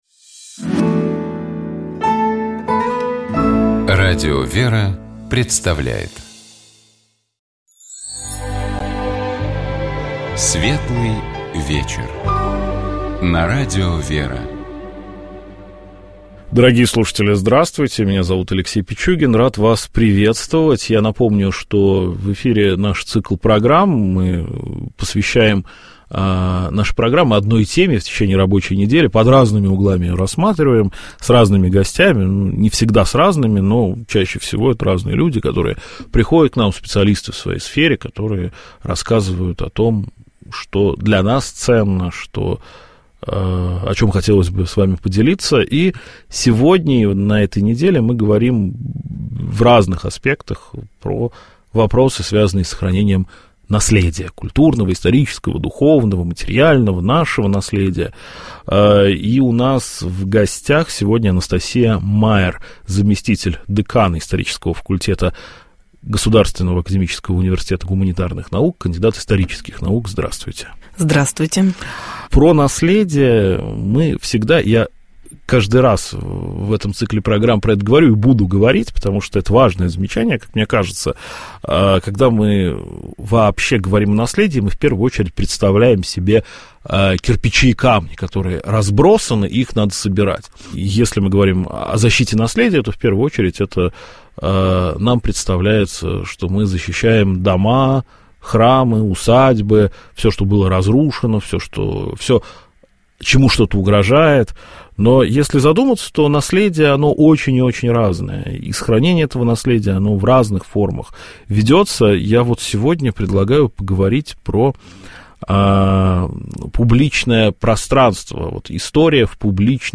Программа «Светлый вечер» — это душевная беседа ведущих и гостей в студии Радио ВЕРА. Разговор идет не о событиях, а о людях и смыслах.